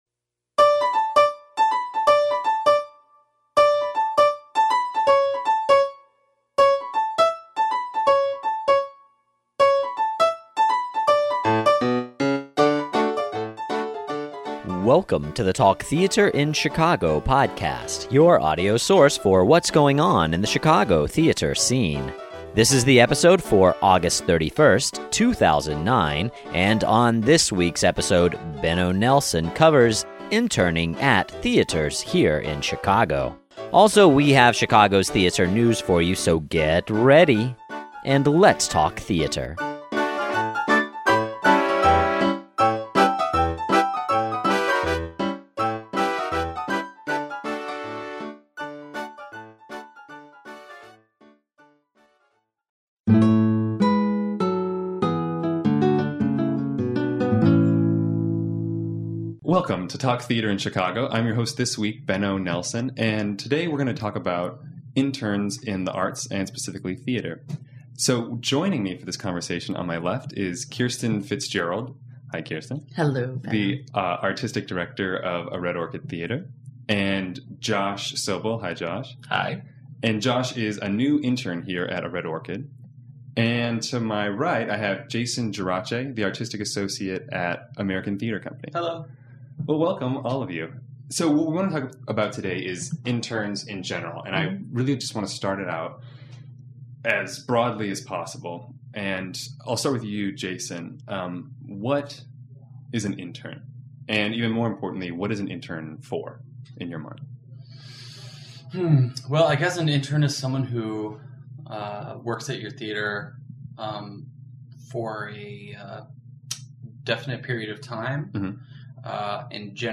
Interns Interview Podcast